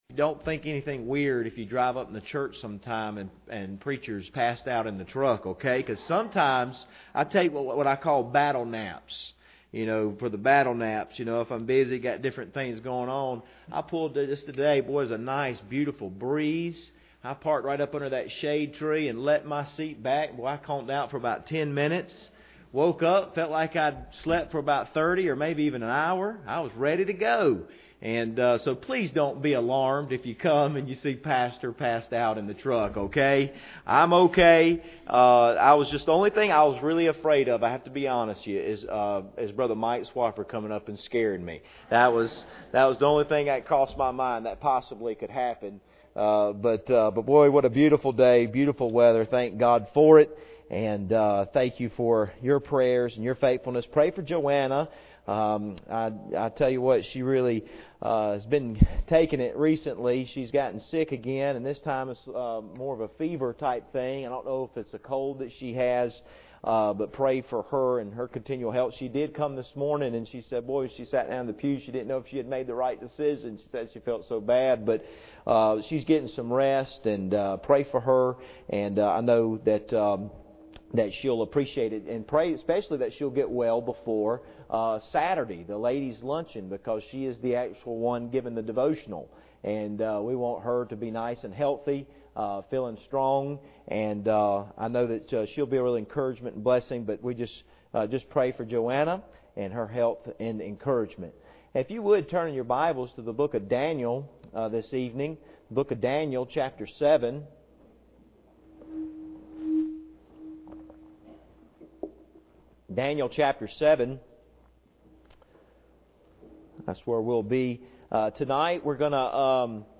Passage: Daniel 7:9-10 Service Type: Sunday Evening